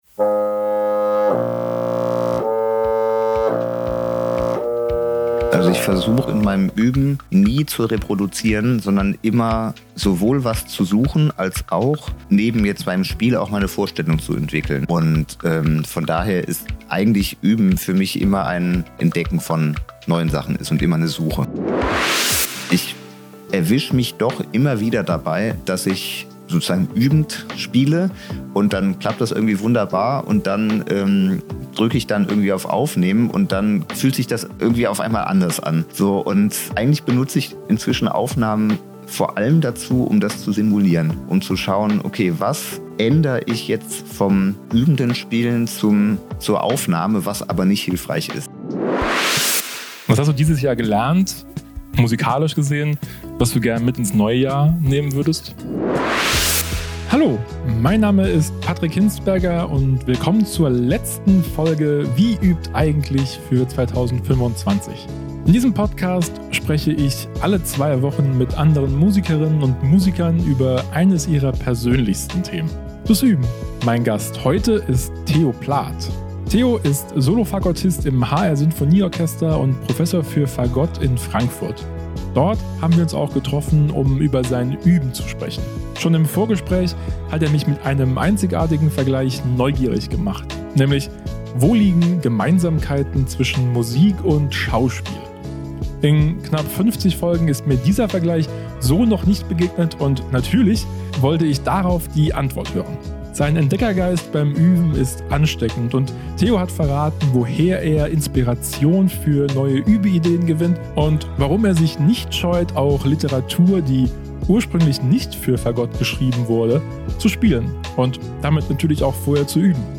Dort haben wir uns auch getroffen, um über sein Üben zu sprechen. Schon im Vorgespräch hat er mich mit einem einzigartigen Vergleich neugierig gemacht - nämlich: Wo liegen Gemeinsamkeiten zwischen Musik und Schauspiel?